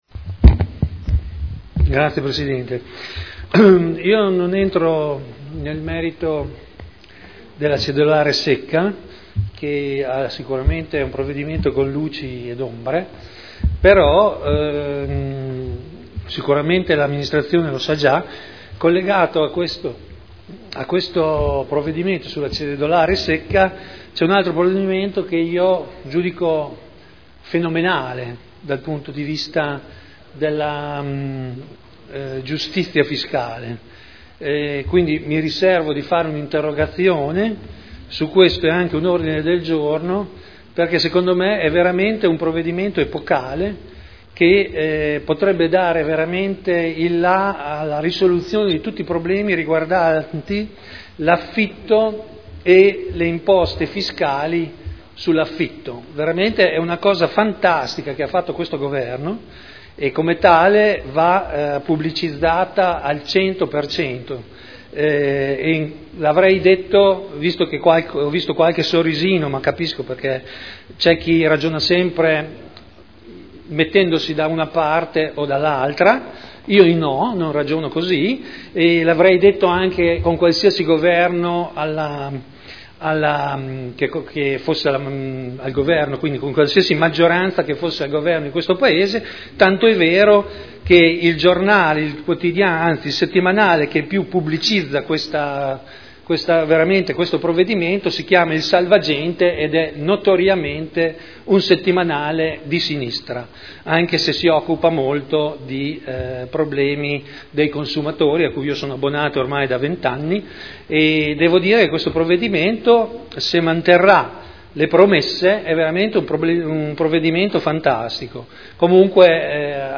Seduta del 27/06/2011. Interrogazione delle consigliere Morini e Urbelli (P.D.) avente per oggetto: “Effetti della cd. “cedolare secca” sul mercato degli affitti” Dibattito